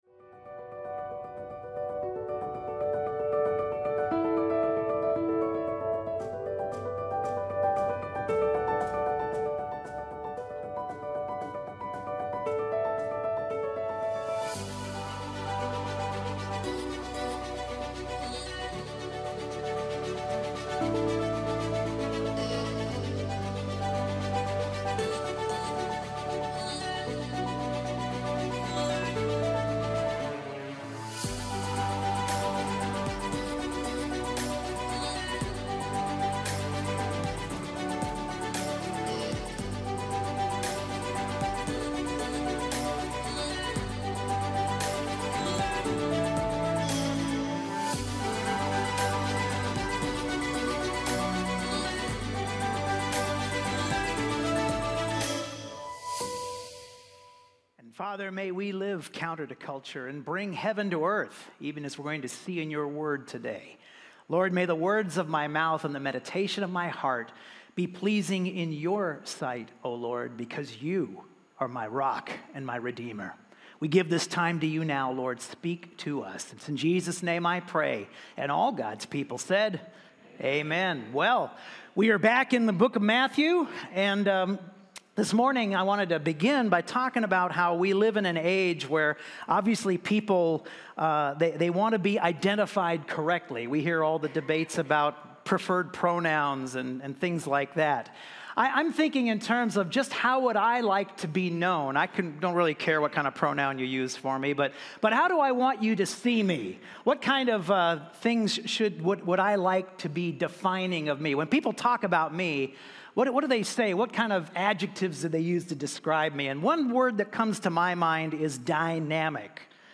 Sermons | CrossWinds Church